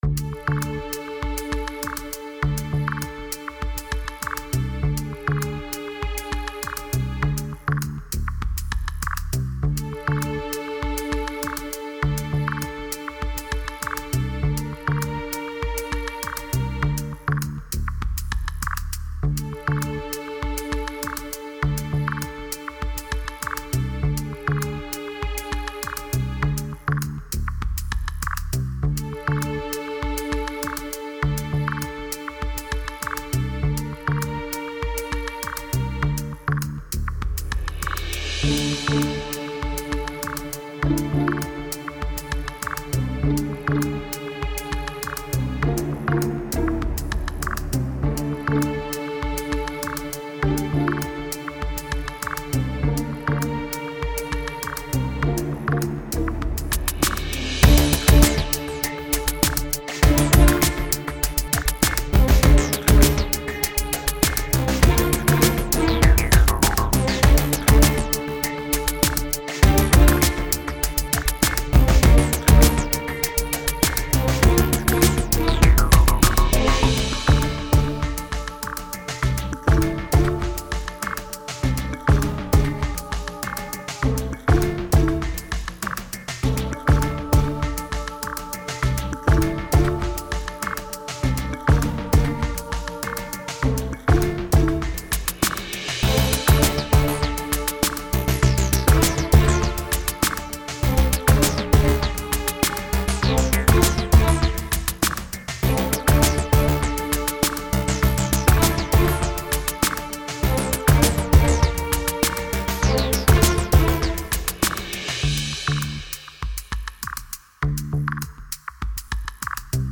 stealth
electronic
strings
sneaky